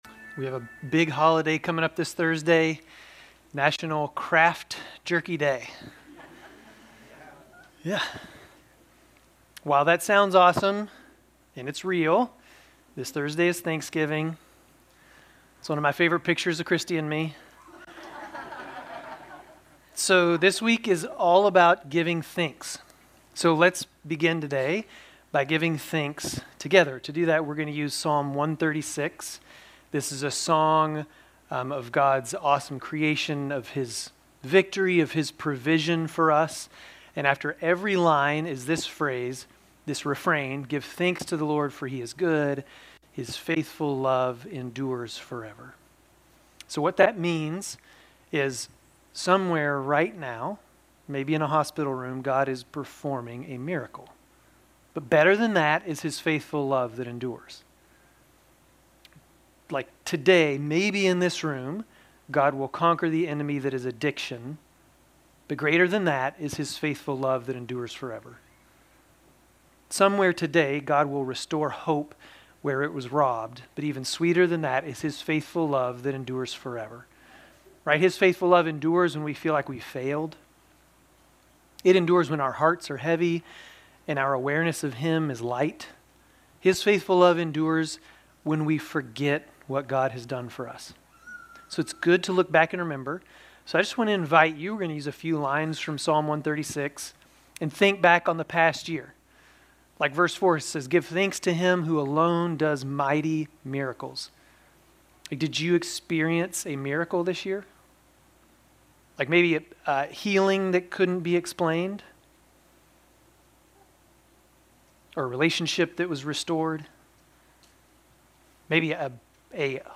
Grace Community Church Dover Campus Sermons 11_23 Dover Campus Nov 24 2025 | 00:25:13 1x 00:00 / 00:25:13 Subscribe Share RSS Feed Share Link Embed